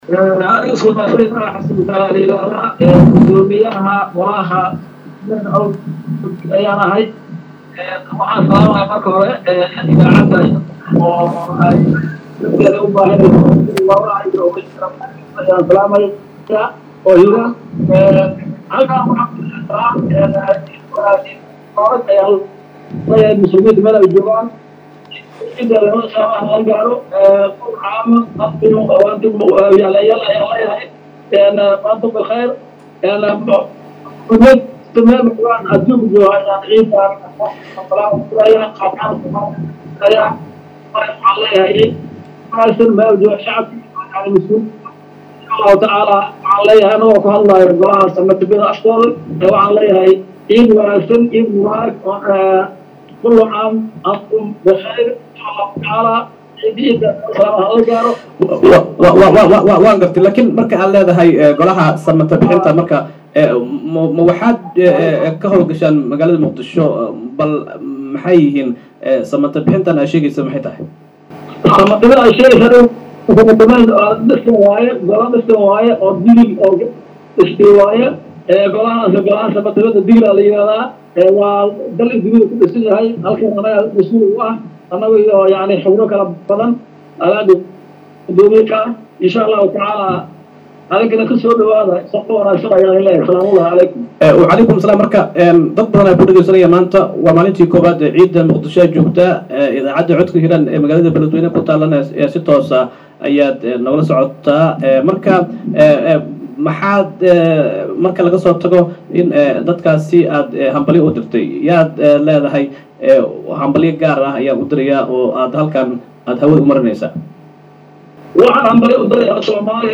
wareysi-ku-saabsan-cida-samdata-bixinta-somaliya-.mp3